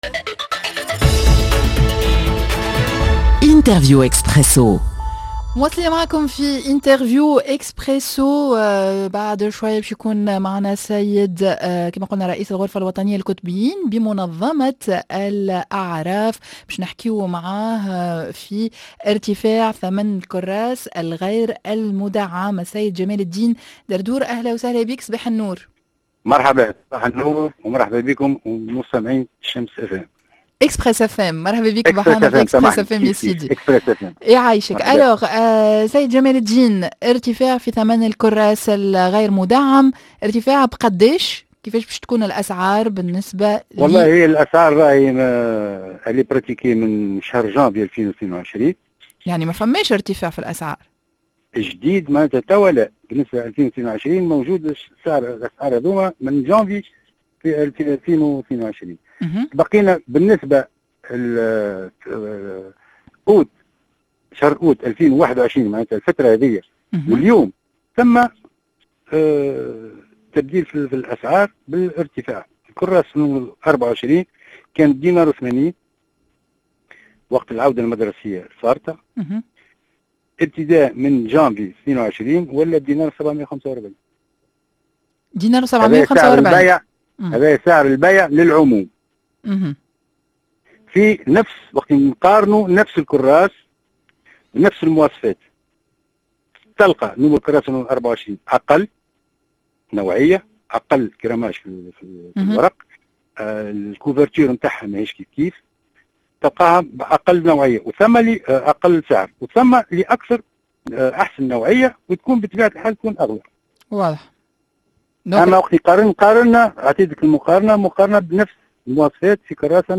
إرتفاع في ثمن الكراس غير المدعم.. و قفزة في أسعار الأدوات المدرسية معانا عبر الهاتف